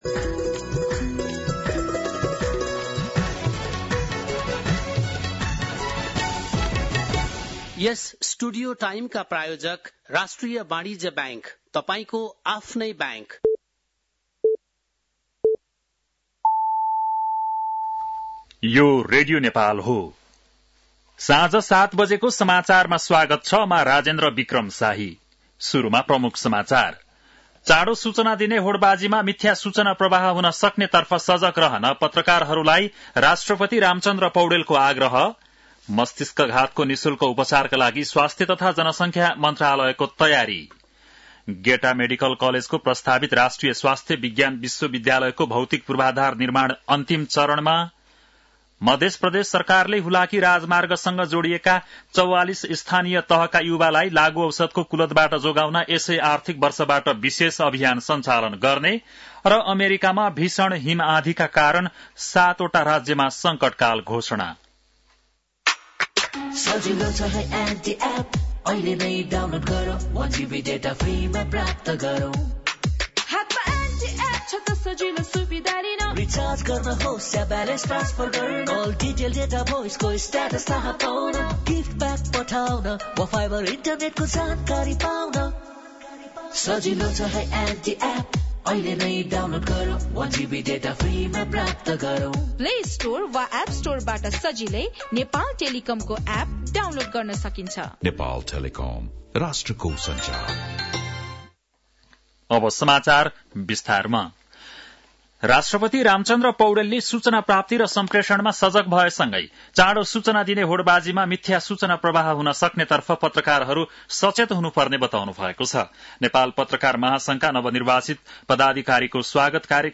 बेलुकी ७ बजेको नेपाली समाचार : २३ पुष , २०८१